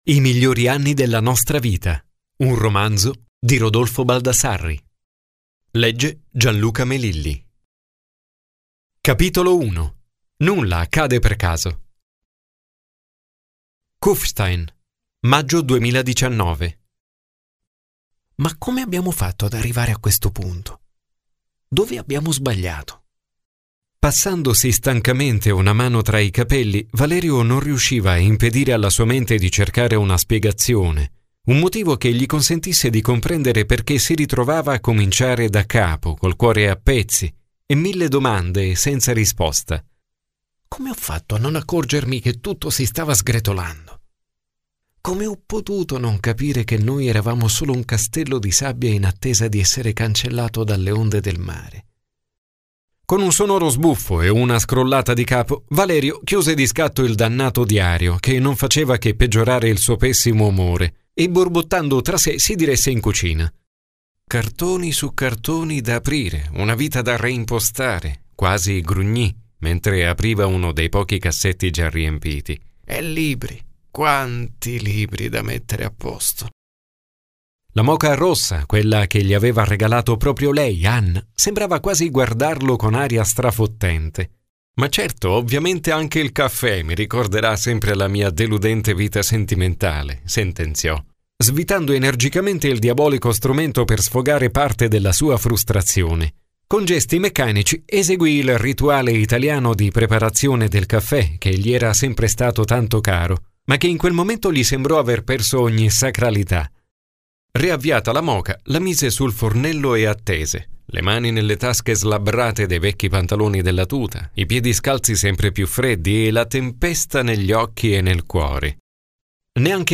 Ascolta l'incipit del romanzo
incipit.mp3